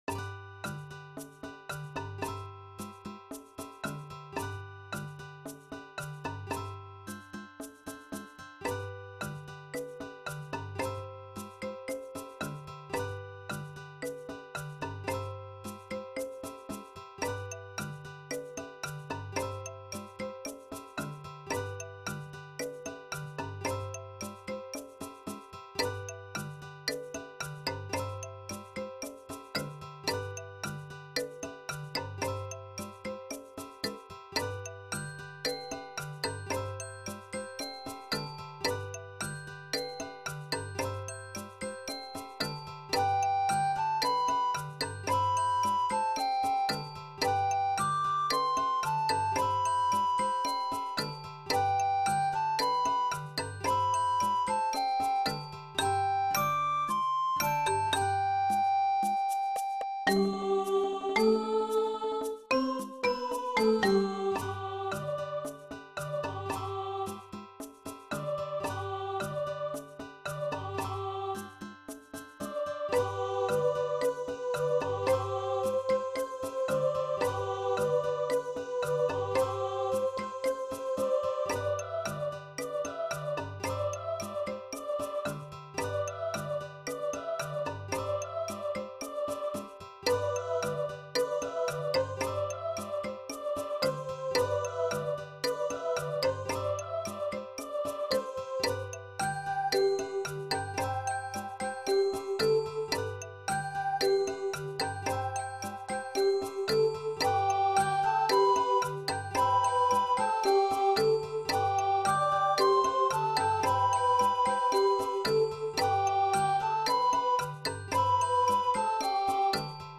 Style: Lyrical
Instrumentation: Orff Ensemble + Chorus